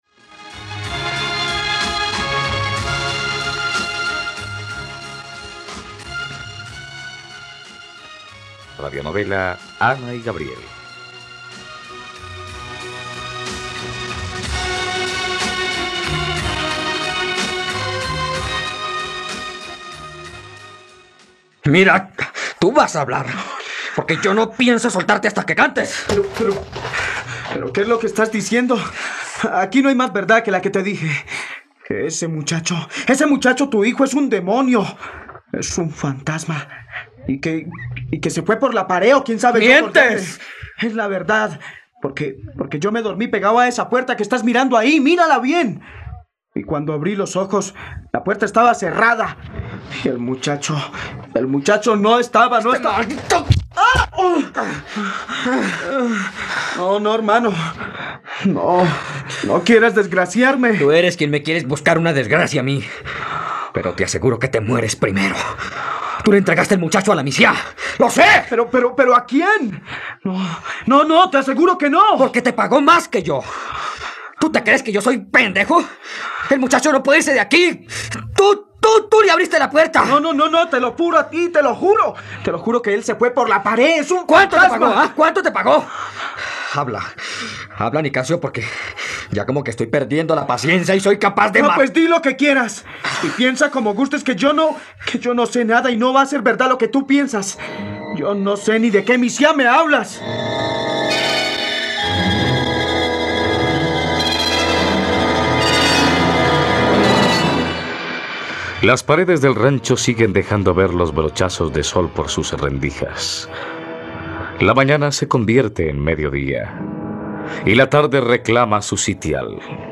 ..Radionovela. Escucha ahora el capítulo 92 de la historia de amor de Ana y Gabriel en la plataforma de streaming de los colombianos: RTVCPlay.